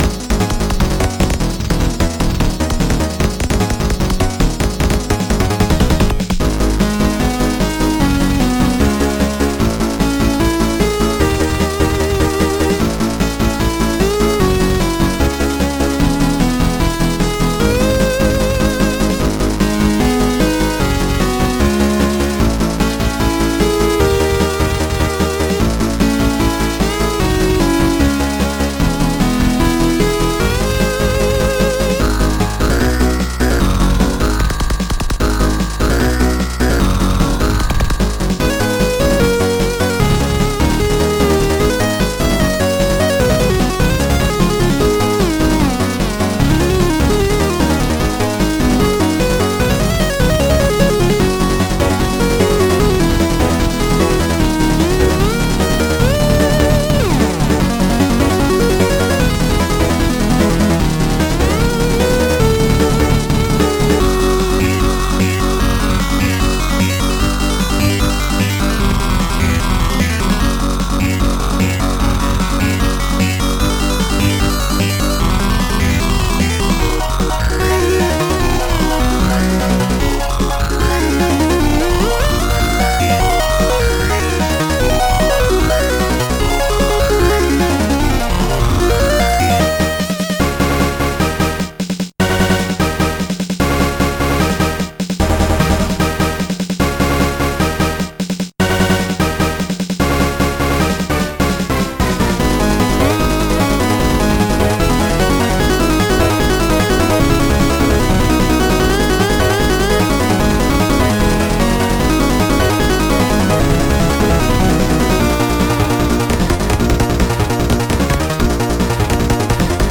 Uptempo, energetic, 4-channel army game style Amiga game chiptune.